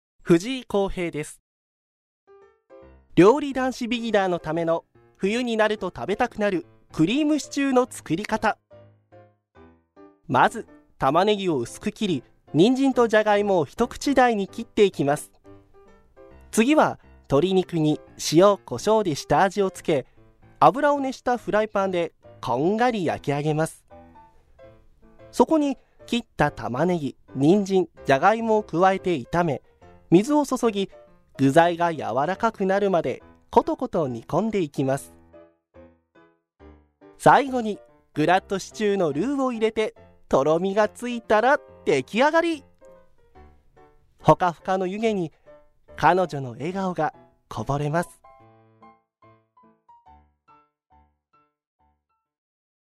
ボイスサンプル
• 明るく爽やかな声
• 音域：高～中音
• 声の特徴：さわやか、明るい
• 番組
爽やか・ソフトな声質